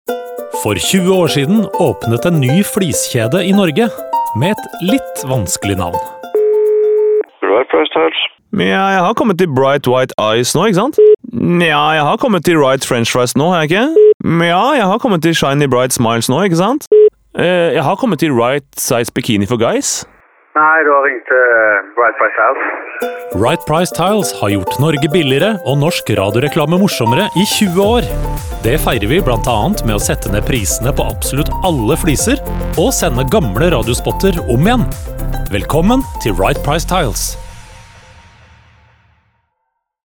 Telefonsamtaler er et grep som er brukt en del i radioreklame, som også gjør jobben enda mer krevende for neste annonsør ut.